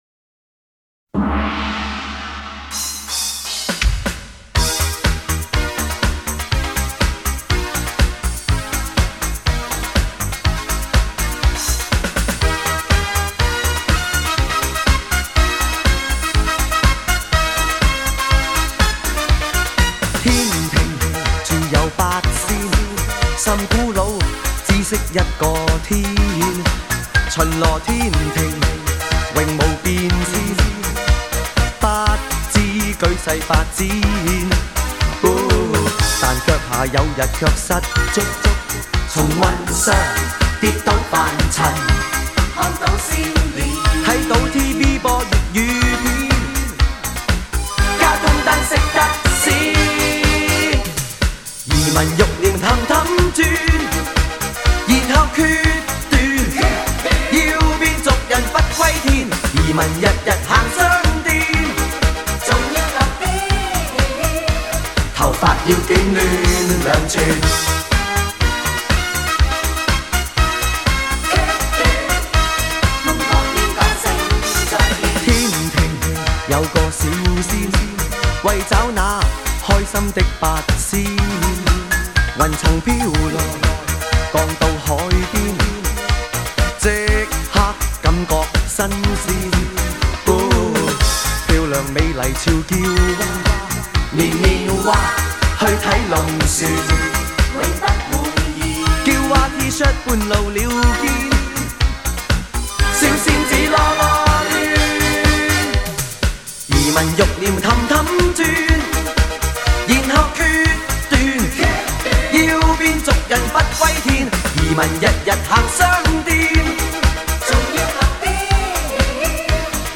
音乐类型：通俗流行［国内］